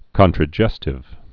(kŏntrə-jĕstĭv)